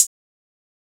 Southside Closed Hatz (12).wav